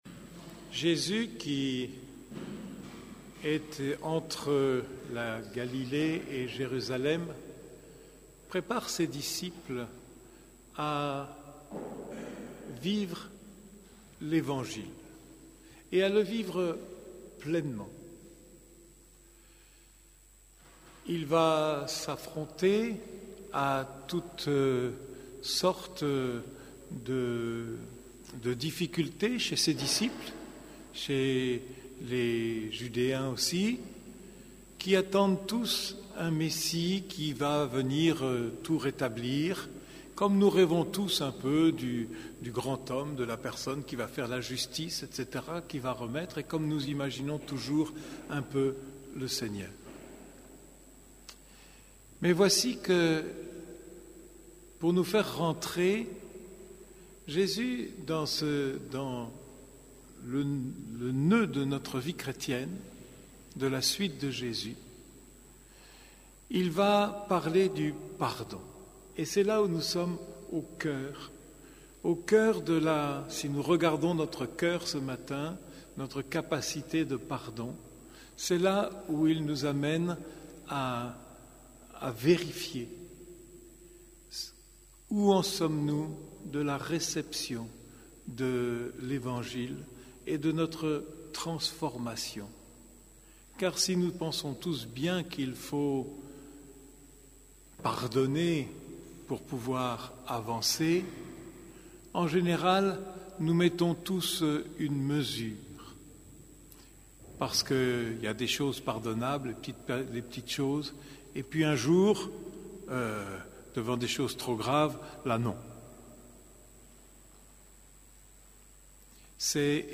Homélie du 24e dimanche du Temps Ordinaire